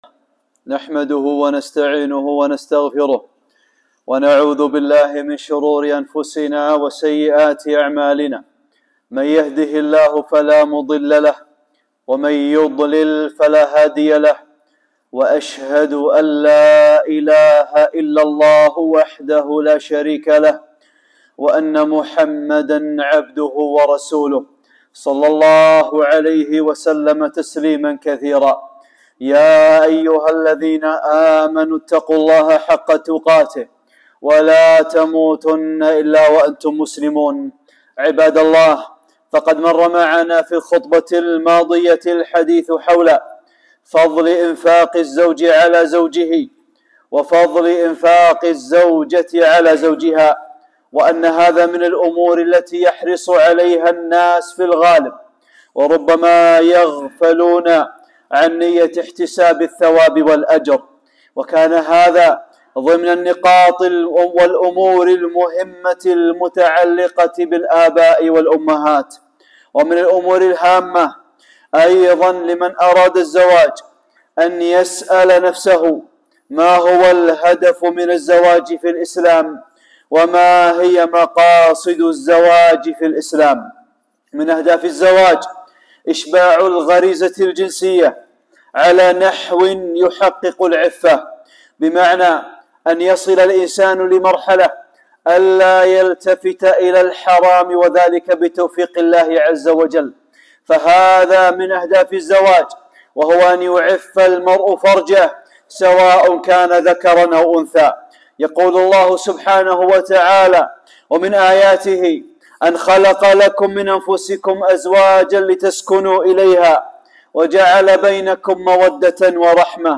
(5) خطبة - نية العفاف - نقاط مهمة للآباء والأمهات